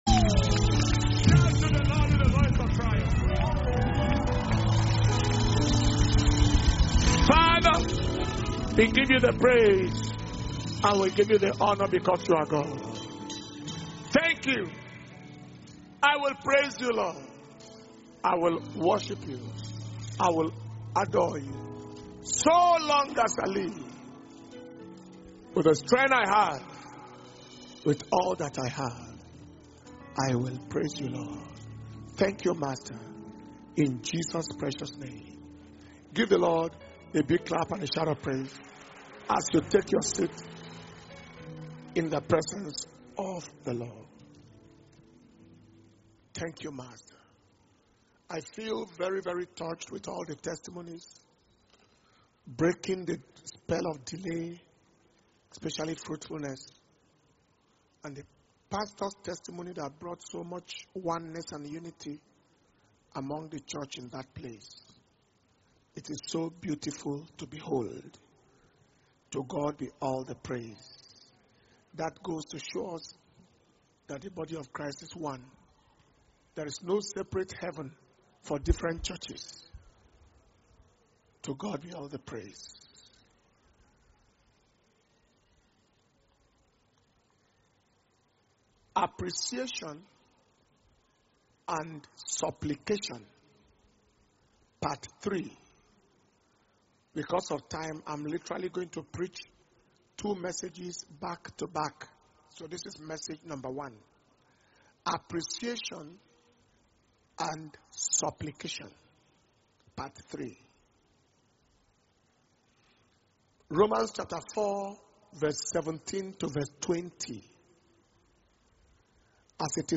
July 2021 Testimonies And Thanksgiving Service/Seven Weeks Of Harvest Glory – 3rd Sunday – Sunday, 25th July 2021